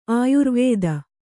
♪ āyurvēda